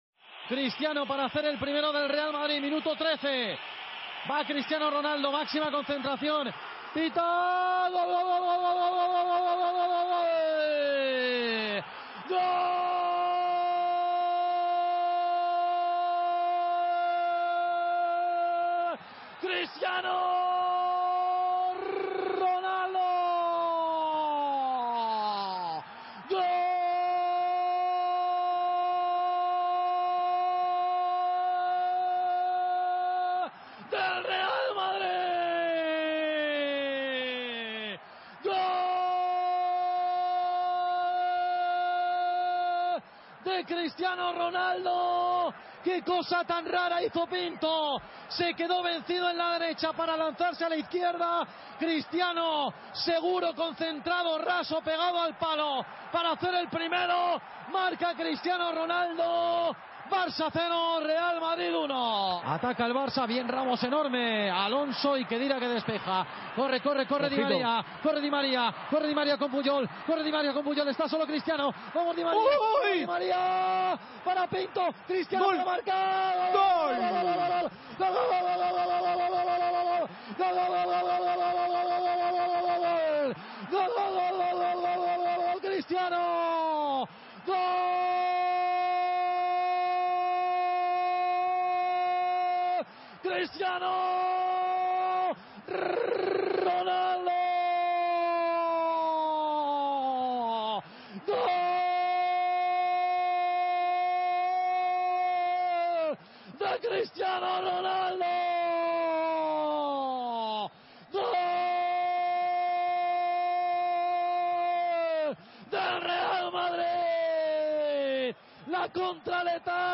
Resumen sonoro del Barcelona, 1 - Real Madrid, 3